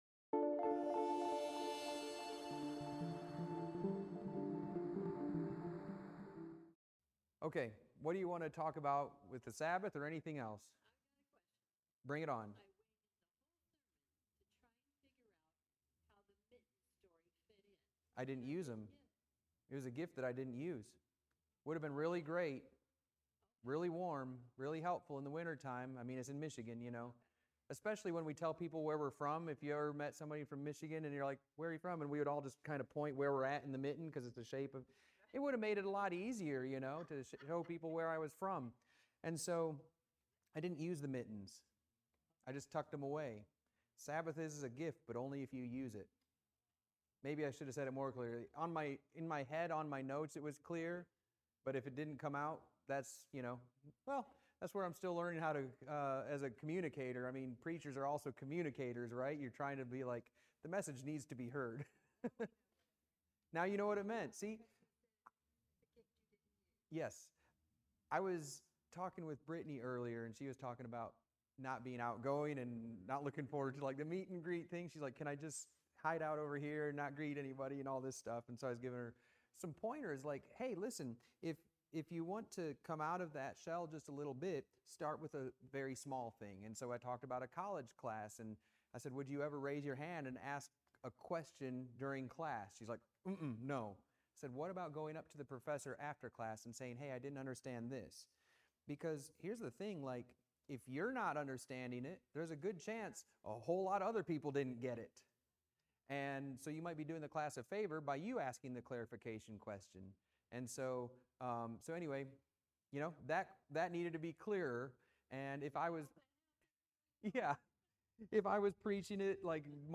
SS Class Audio from "Mittens" Sabbath Sermon #1